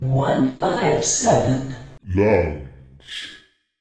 Tag: 论坛 幽默 请求 说话 声带 声音